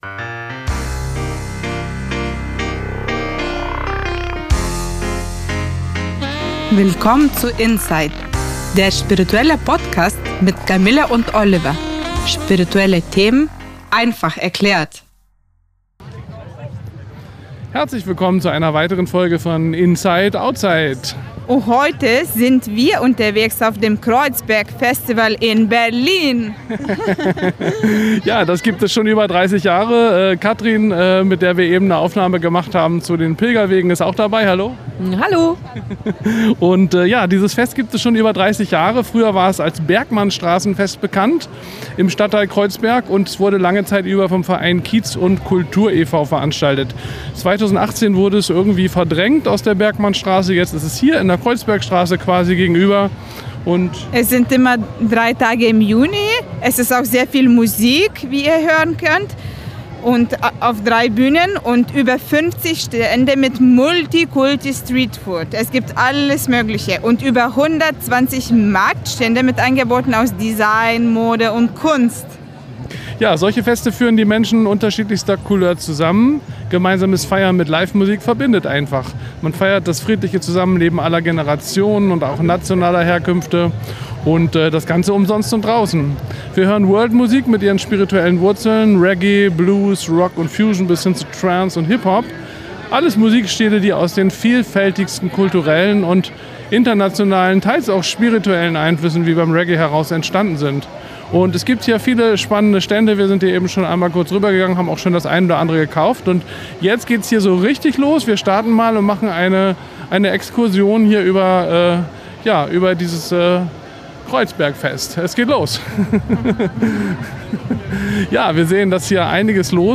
INSIGHT OUTSIDE Auf dem Kreuzberg-Festival (Ausschnitt)
Drei Musik-Bühnen und über 100 Stände mit